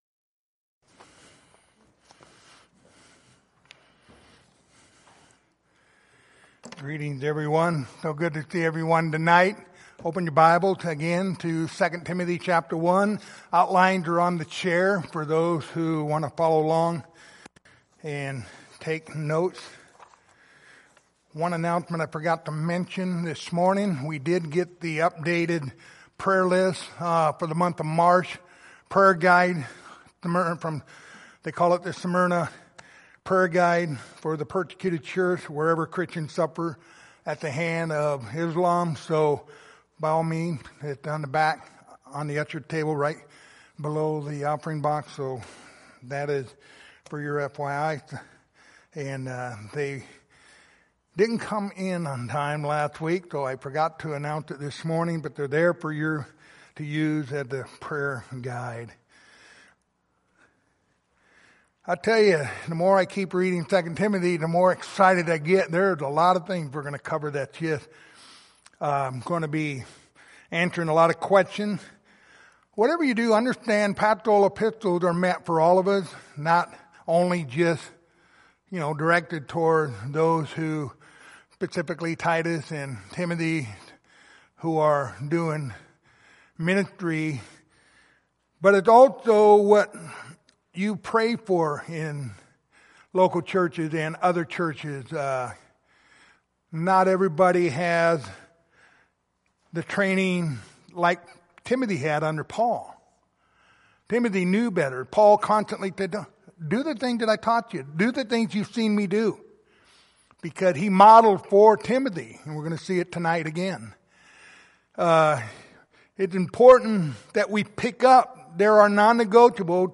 Passage: 2 Timothy 1:13-14 Service Type: Sunday Evening